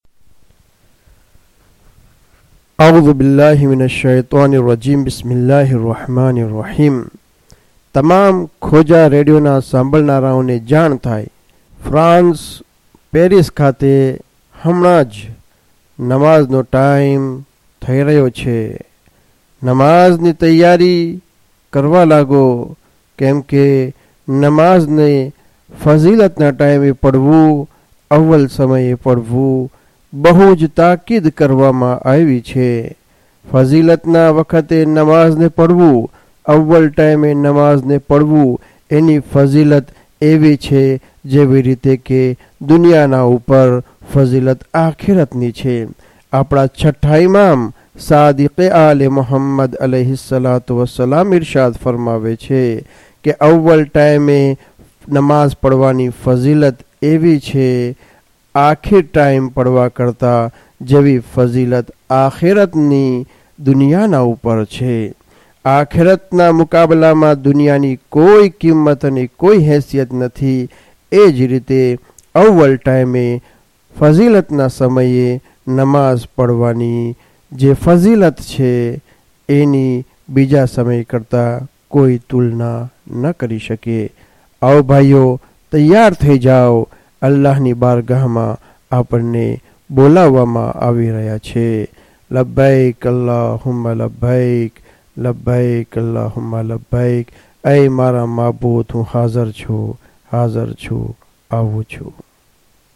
2 azan paris.mp3